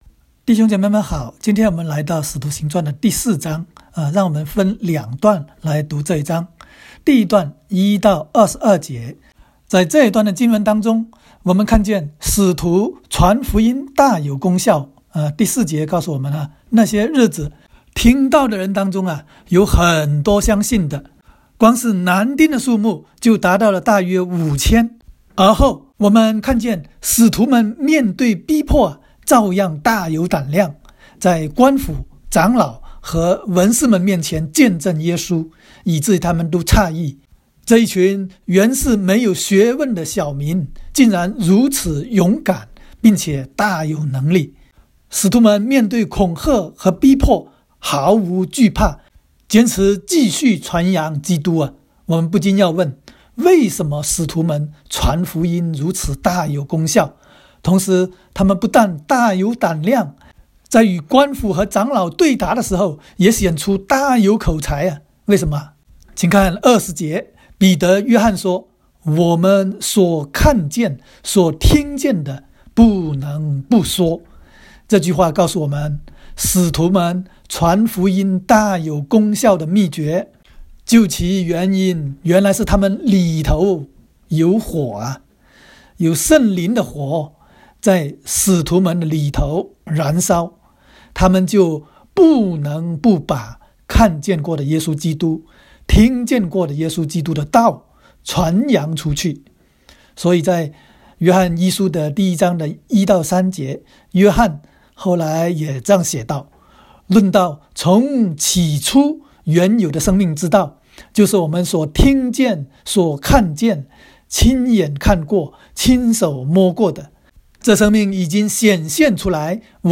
徒04（讲解-国）.m4a